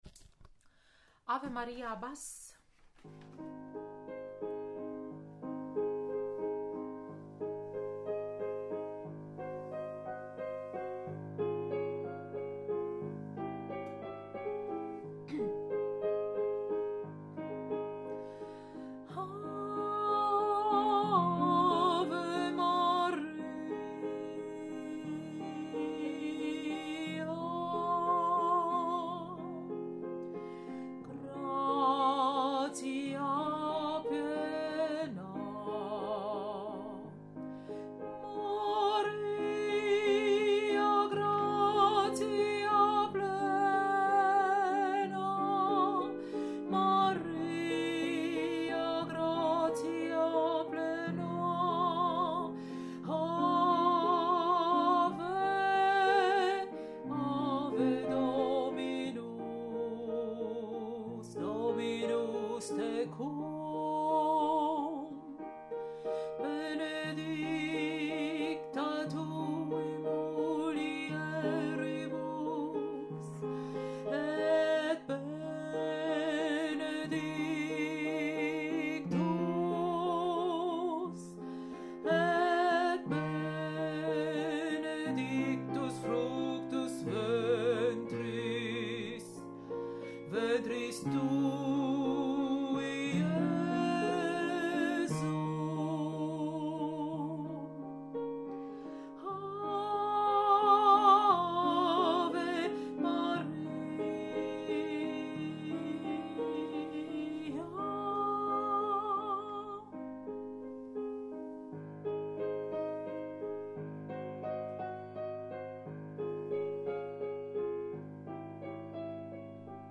Ave Maria – Bass
Ave-Maria-Bass.mp3